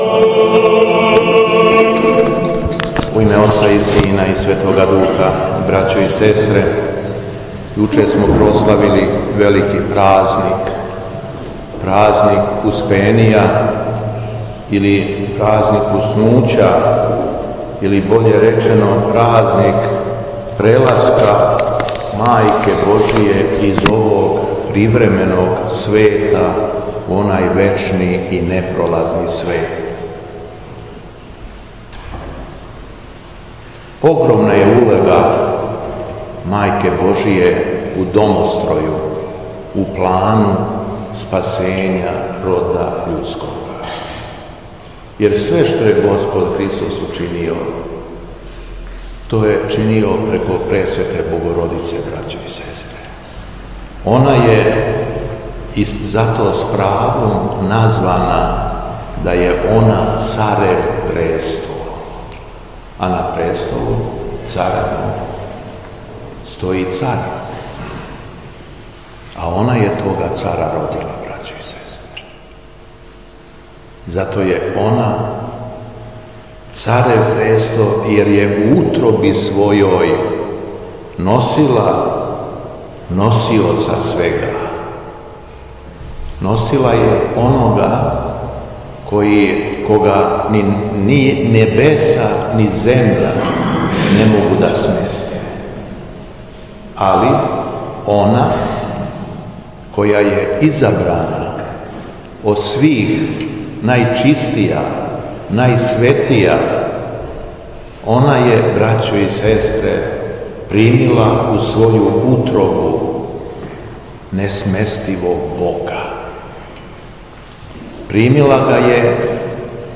Беседа Његовог Преосвештенства Епископа шумадијског г. Јована